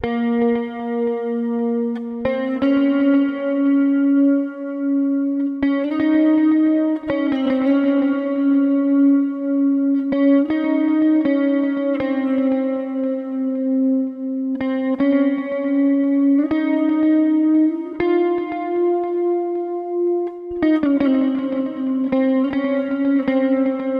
吉他80Bpm
Tag: 80 bpm Pop Loops Guitar Electric Loops 4.04 MB wav Key : Unknown